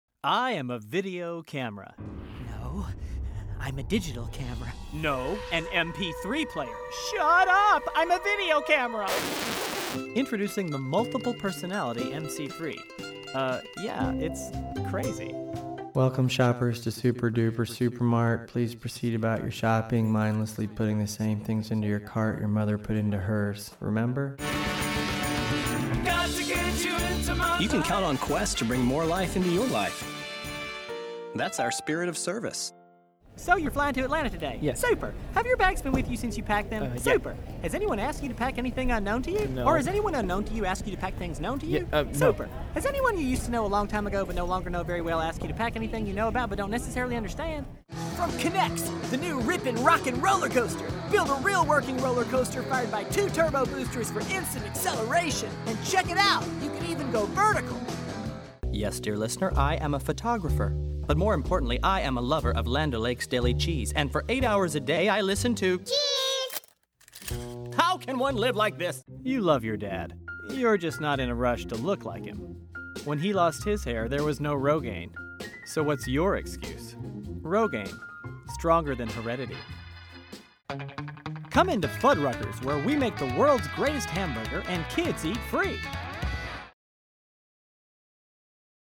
voiceover : animation